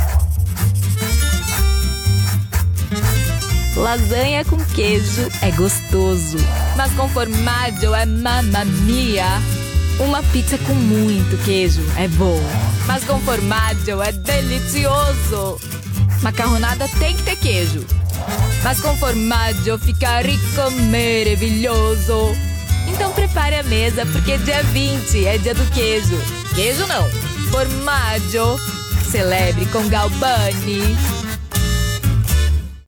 Sprechprobe: eLearning (Muttersprache):
I'm a Brazilian voice actress with a naturally youthful tone, perfect for portraying children (boys and girls), teens, and young adults. I specialize in expressive, colorful character voices — from playful and animated to sweet and sincere.